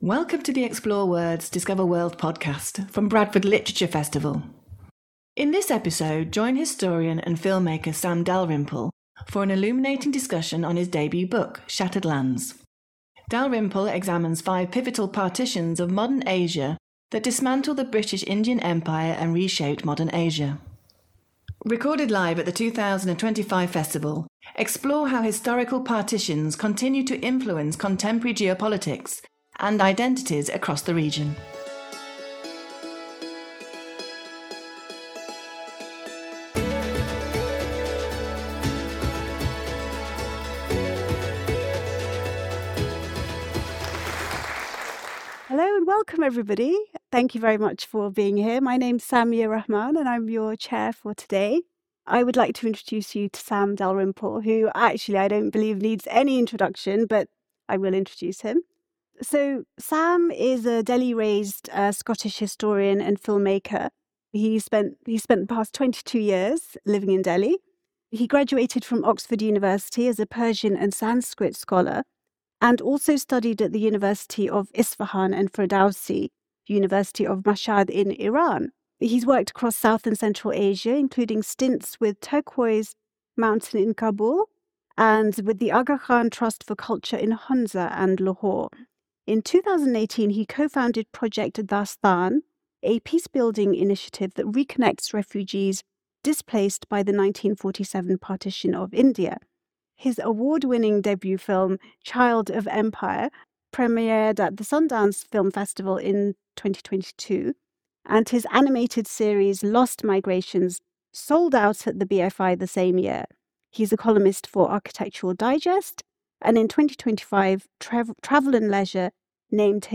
an illuminating discussion